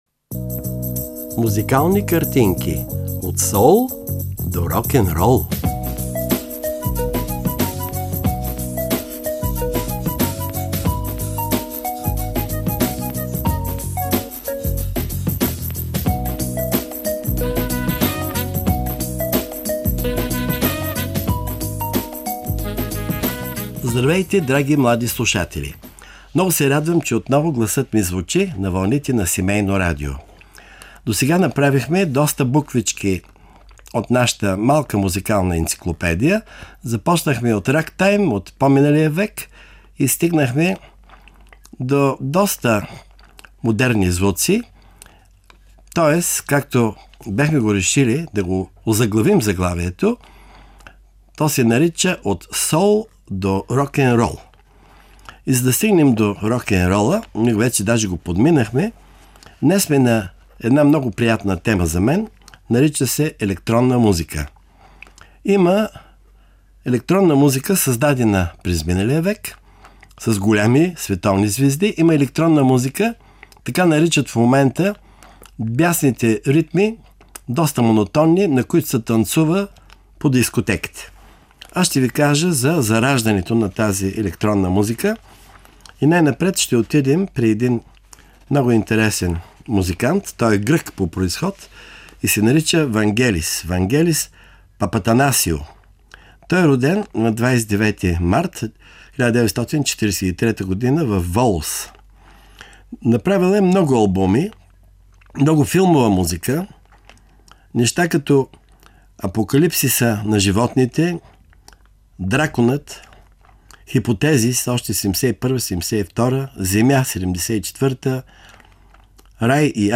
В това издание на рубриката „Музикални картинки“ ще разберете кои са най-известните композитори на електронна музика и ще чуете най-популярните им изпълнения.